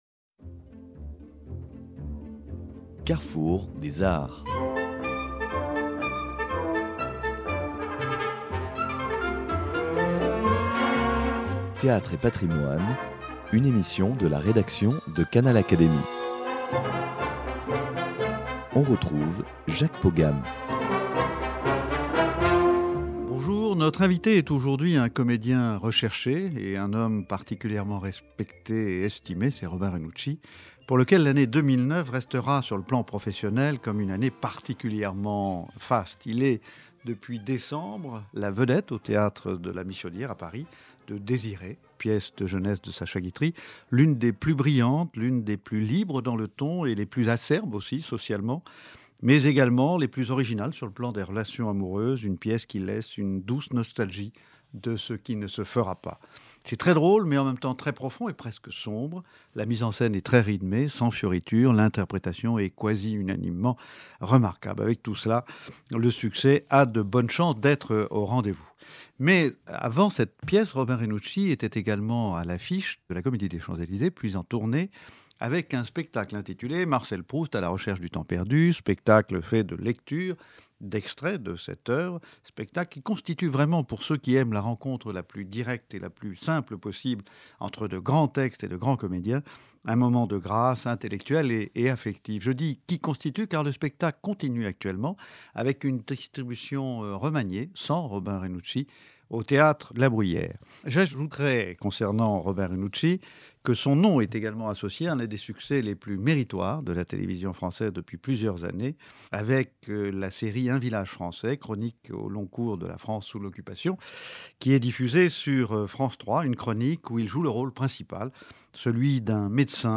Dans cet entretien, le comédien relate tour à tour les succès de ses dernières interventions au théâtre, ainsi que son goût pour l’art et la transmission des savoirs au plus grand nombre.